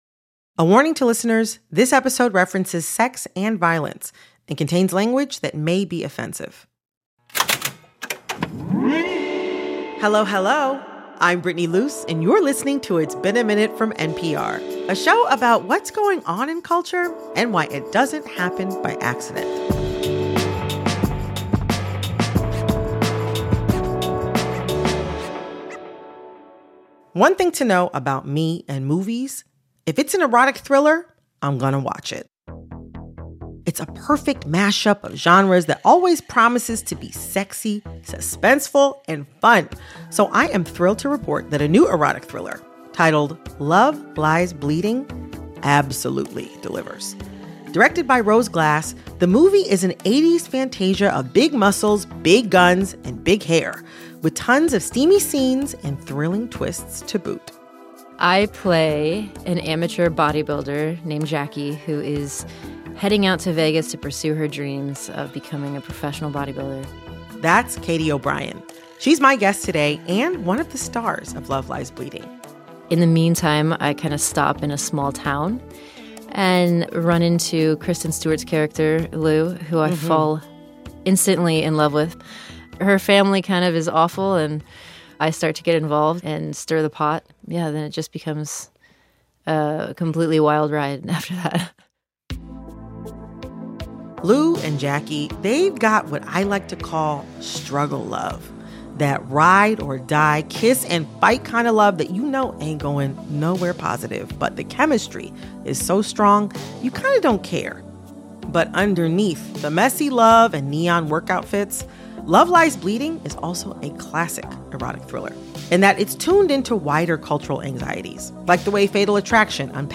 It follows the story of a bodybuilder named Jackie, played by Katy O'Brian, who falls madly in love with gym manager Lou, played by Kristen Stewart. Host Brittany Luse sat down with Katy O'Brian to talk about strong women and the fantasy of wielding the rage that lurks just under the surface.